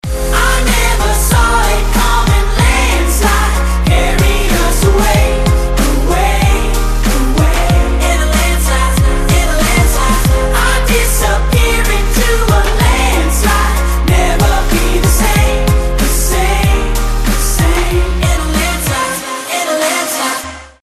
• Качество: 256, Stereo
dance
EDM
vocal
Eletcronic